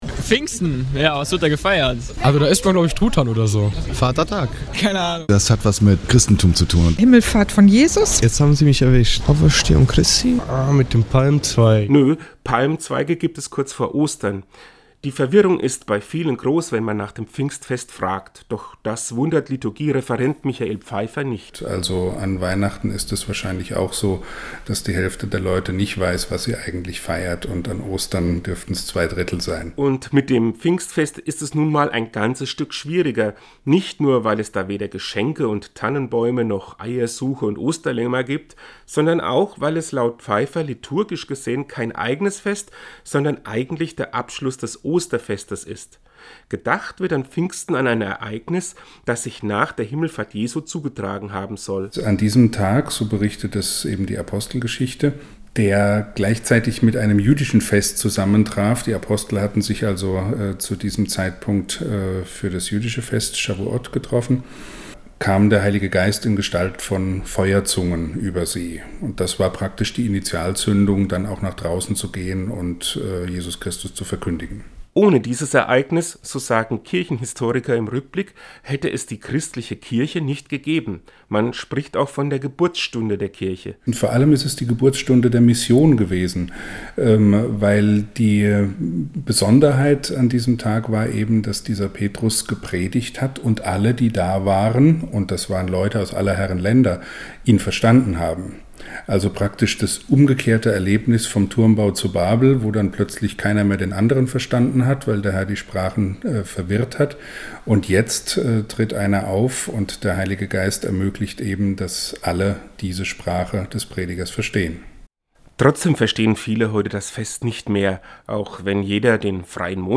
Seinen Radiobeitrag finden Sie unten zum Download!